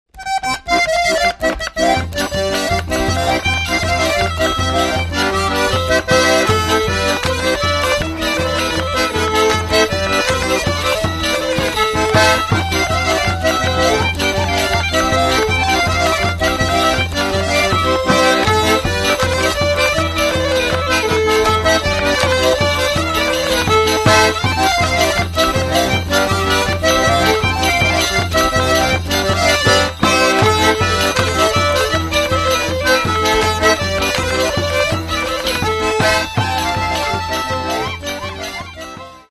Каталог -> Народная -> Инструментальная